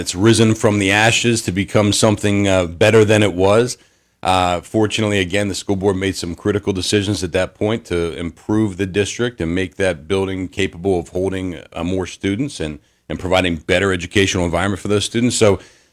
In an interview on Indiana in the Morning